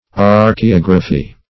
Search Result for " archaeography" : The Collaborative International Dictionary of English v.0.48: Archaeography \Ar`ch[ae]*og"ra*phy\ ([aum]r`k[-e]*[o^]g"r[.a]*f[y^]), n. [Gr.